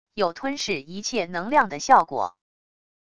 有吞噬一切能量的效果wav音频